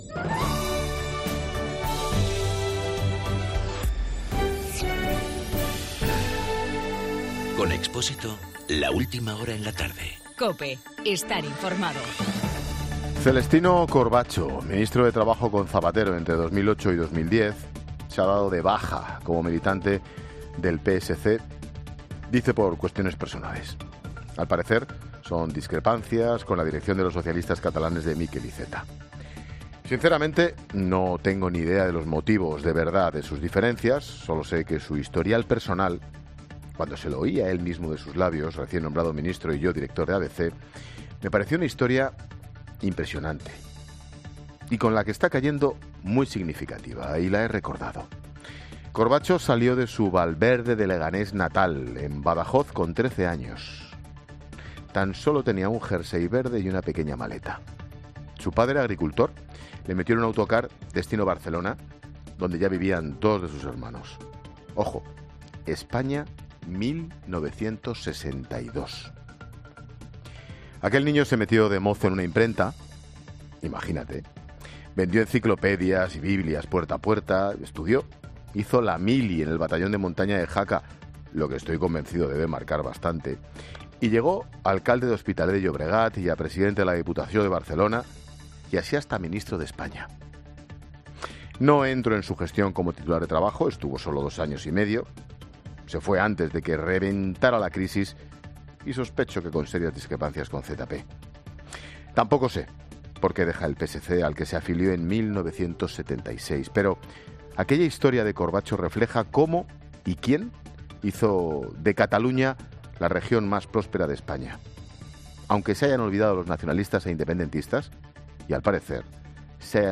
AUDIO: El comentario de Ángel Expósito sobre el político socialista que deja el PSC.